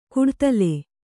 ♪ kuḍtale